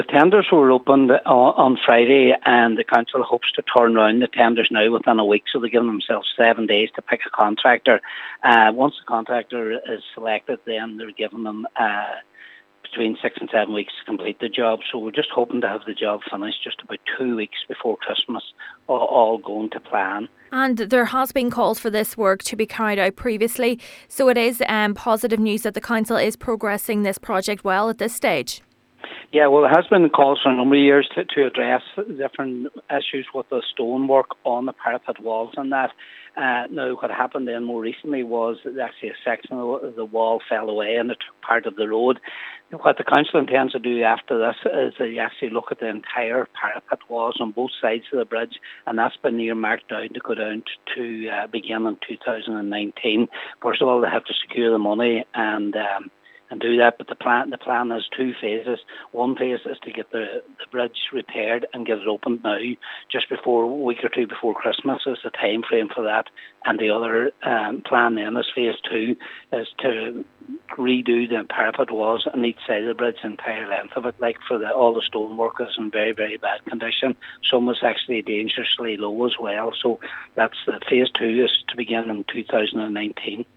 Cathaoirleach of the Stranorlar Municipal District is Councillor Patrick McGowan: